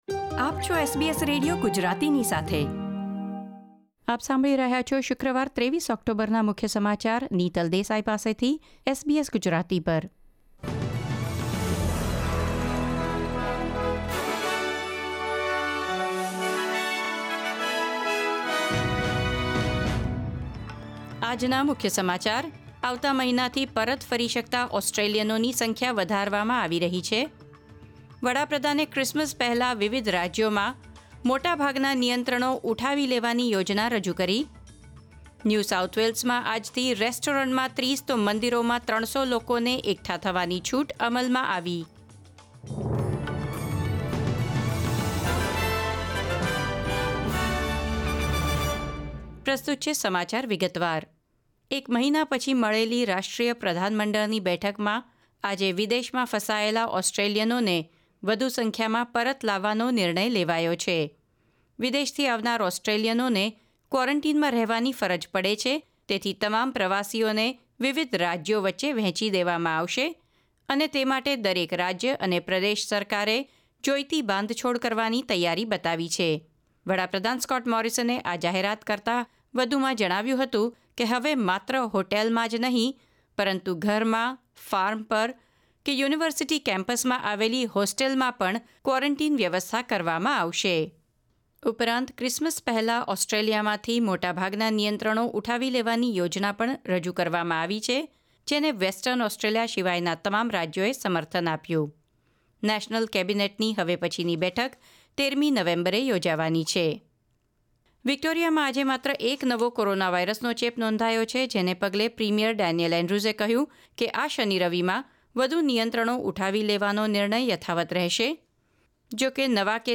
gujarati_2310_newsbulletin.mp3